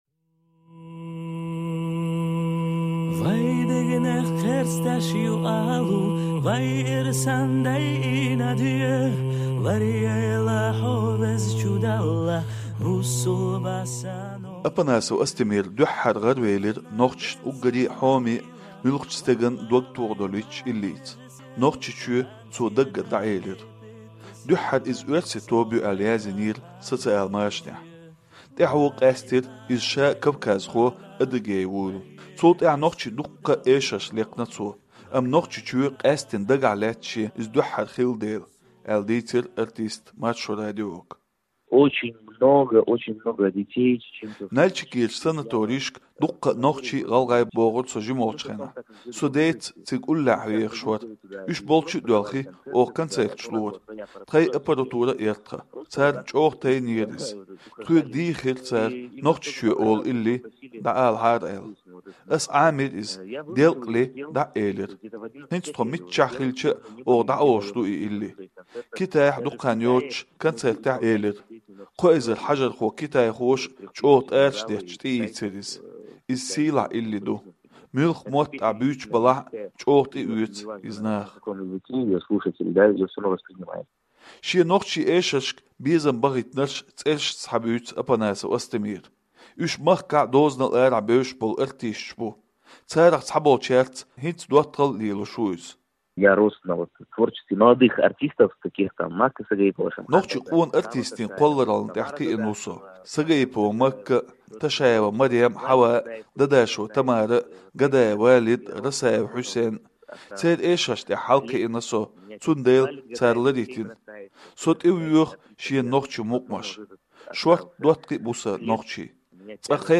Адыгейн иллиалархочо дозалла до шен гаргарло